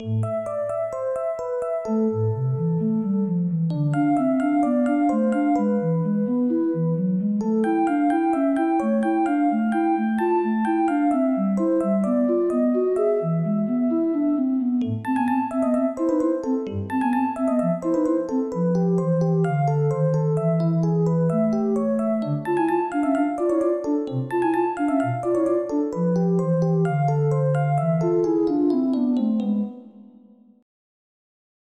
catchy music